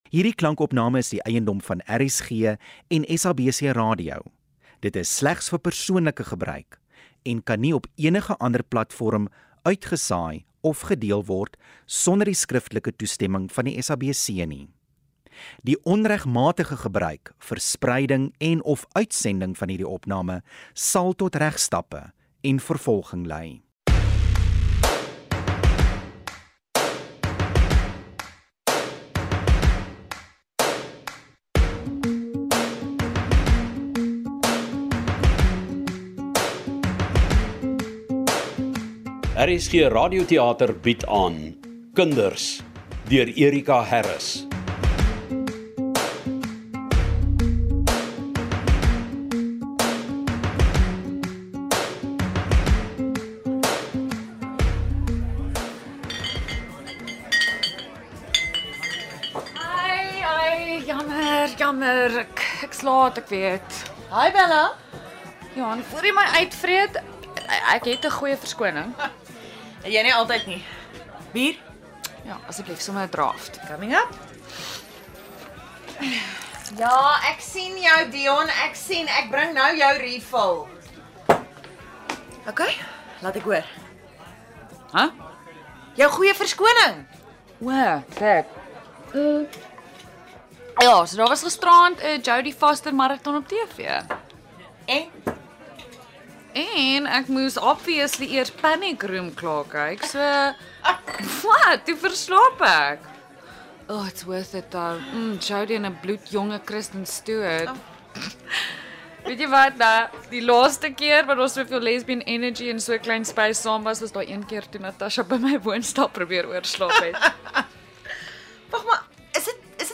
Die sensitiewe, volwasse drama handel oor ‘n eietydse gesin en die uitdagings wat daarmee saamhang.